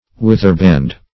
Search Result for " witherband" : The Collaborative International Dictionary of English v.0.48: Witherband \With"er*band`\, n. [Withers + band.]